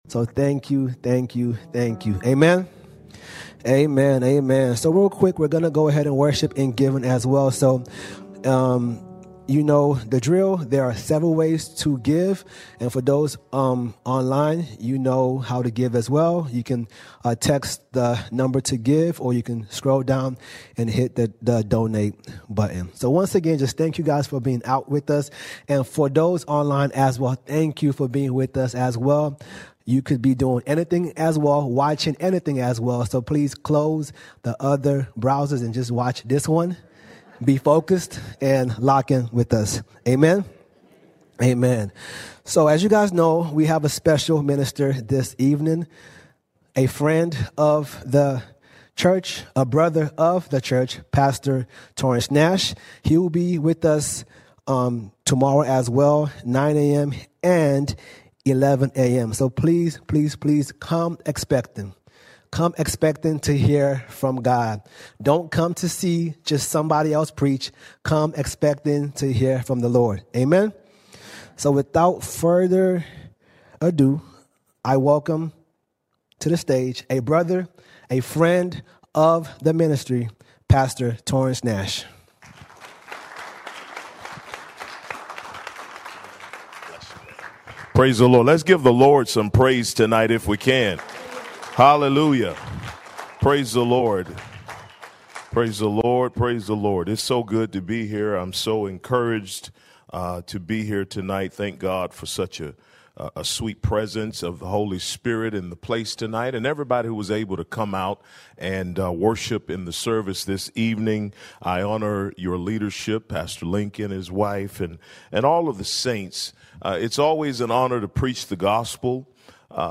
2 March 2026 Series: Guest Speakers All Sermons The Person Of the Holy Spirit The Person Of the Holy Spirit The Holy Spirit is not just a force.